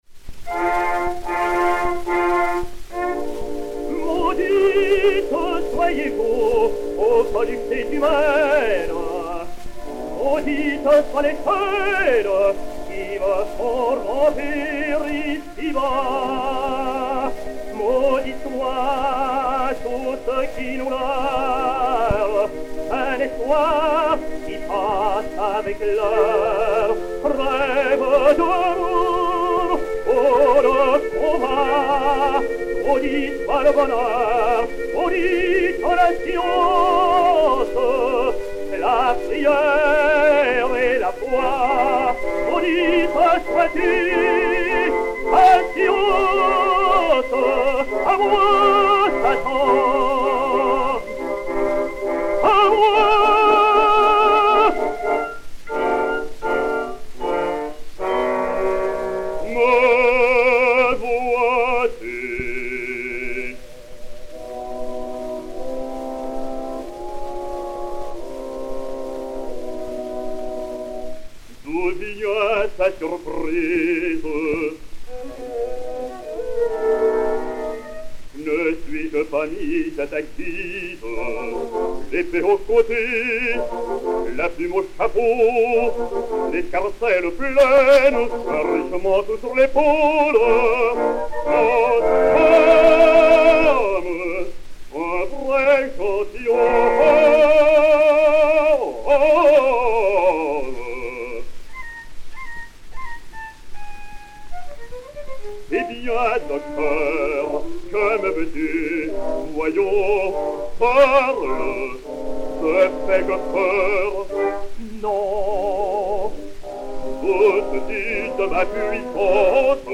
ténor français
Léon Beyle (Faust), André Gresse (Méphistophélès) et Orchestre
Disque Pour Gramophone 34127, mat. 4689o, enr. à Paris en 1905